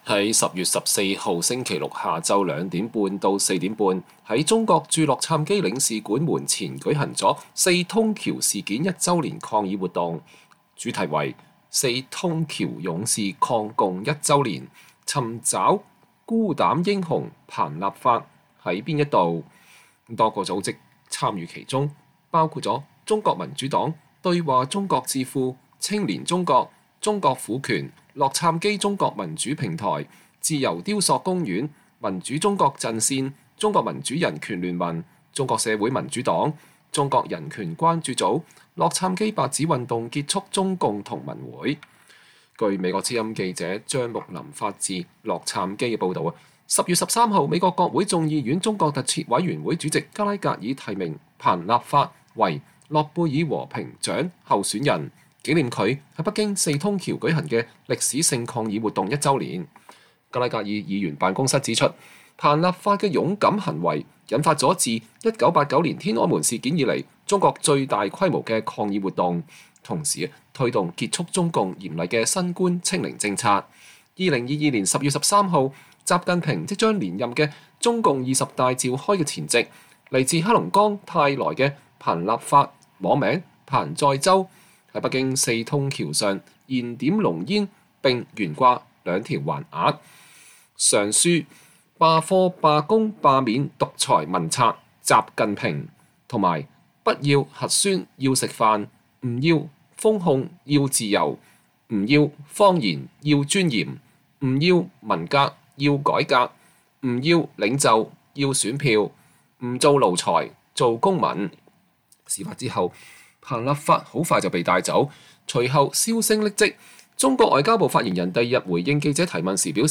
10月14日星期六下午2:30-4:30，在中國駐洛杉磯領事館門前舉行了四通橋事件一週年抗議活動，主題為：“四通橋勇士抗共一週年、尋找孤膽英雄彭立發在哪兒？”